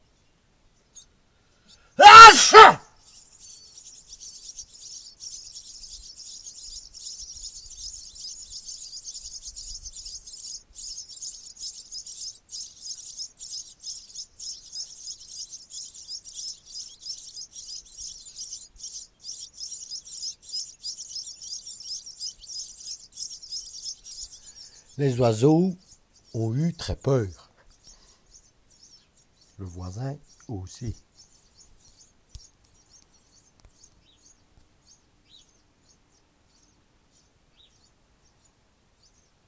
Des oiseaux dans la corniche